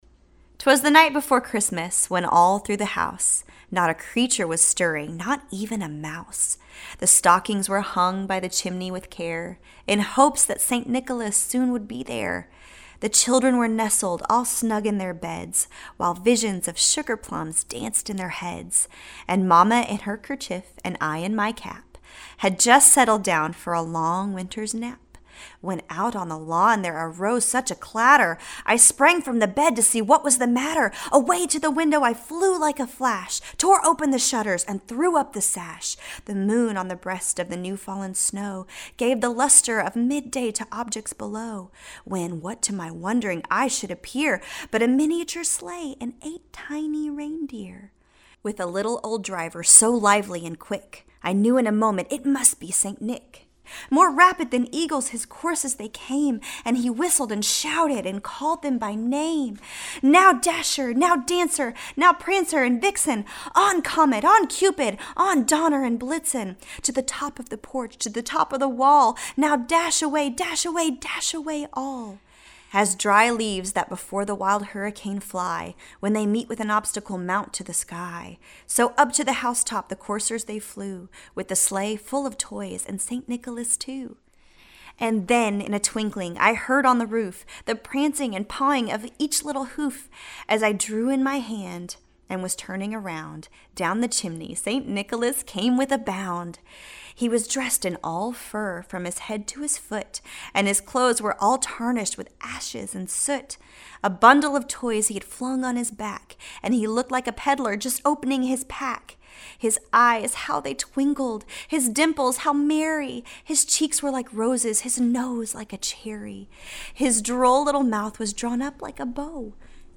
Here’s a solo read of “‘Twas the Night Before Christmas” from Carrie Underwood.
CARRIE UNDERWOOD READS "TWAS THE NIGHT BEFORE CHRISTMAS."
Carrie-Underwood-reads-Twas-the-Night-Before-Christmas.mp3